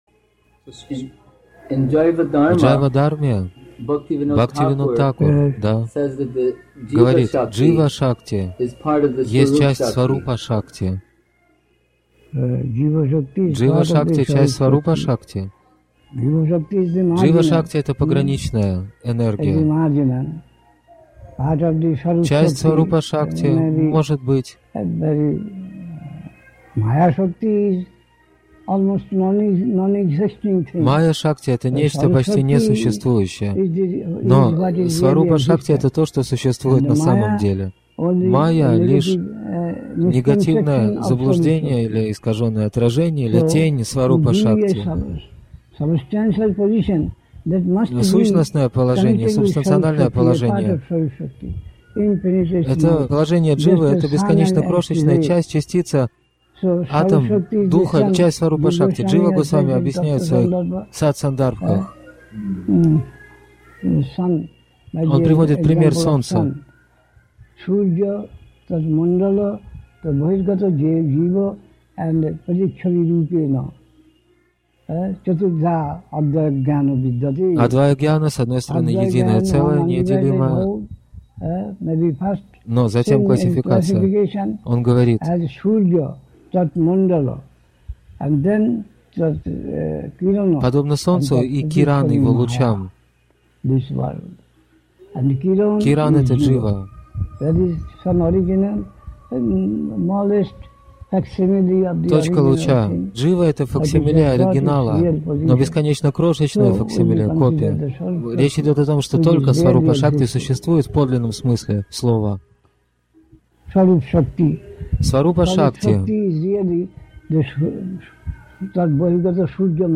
Навадвипа Дхама, Индия)